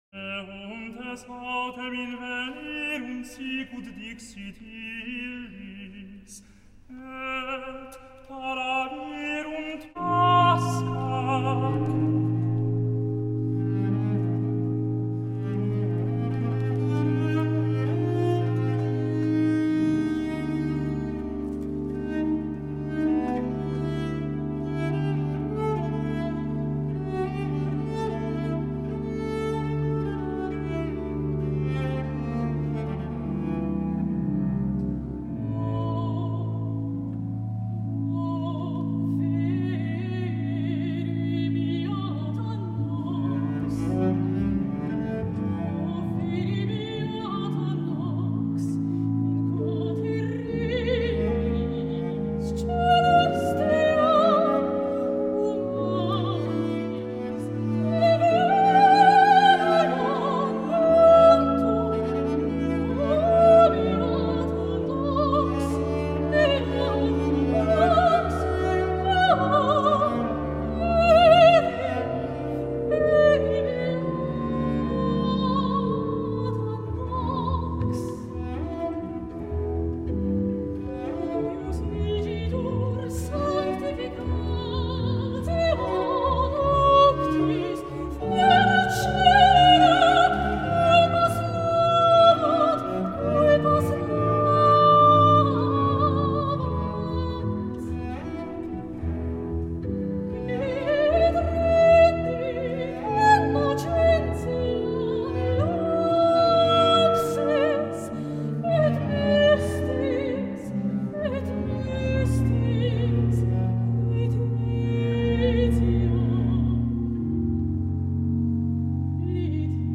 Evangelist
Soprano solo
Violoncello
Organ 3’00”